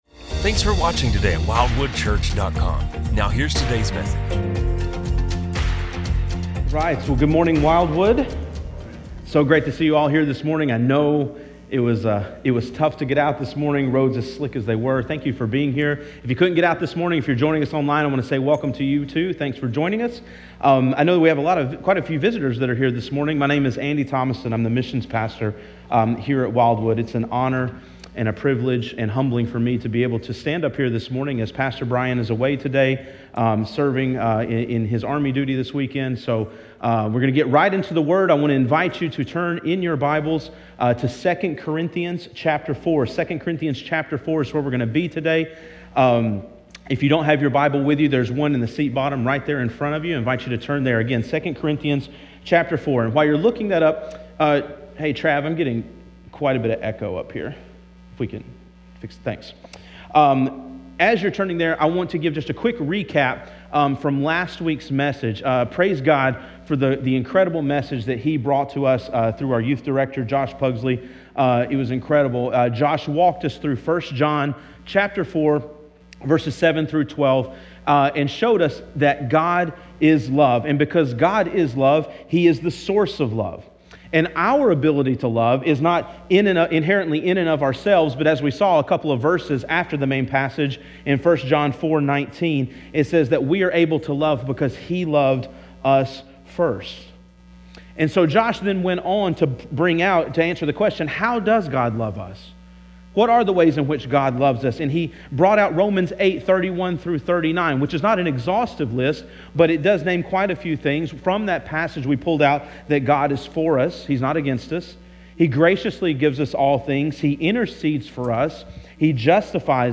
From Series: "Stand Alone Sermons"